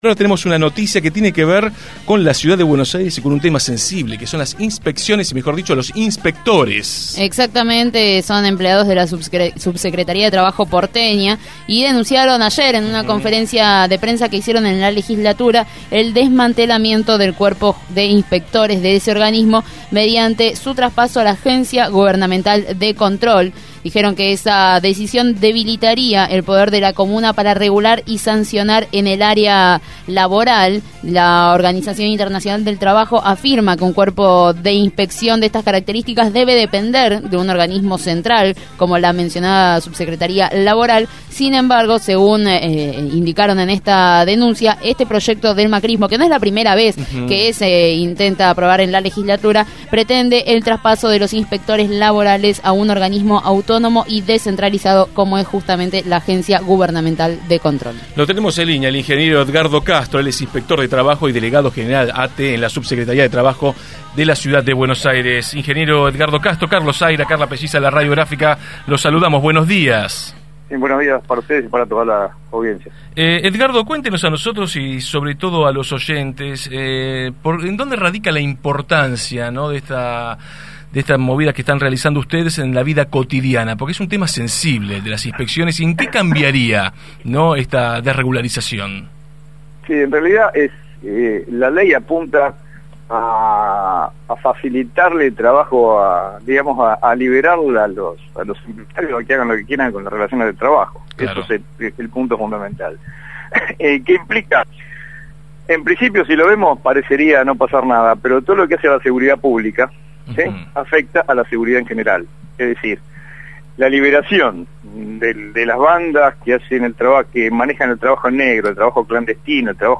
entrevistó